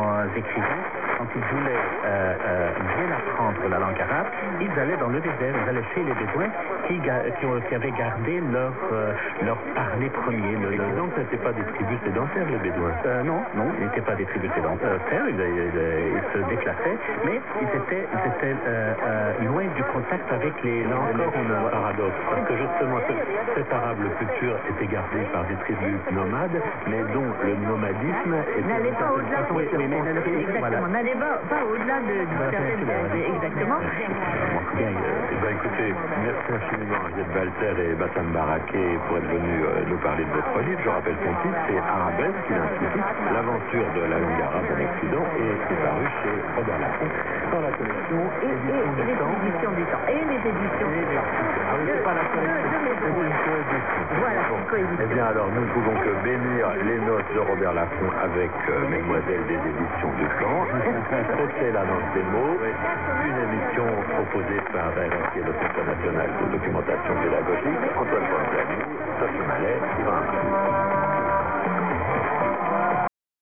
> under CJBC with a talk show and a female host.
A clip of my reception (about 1 min, recorded at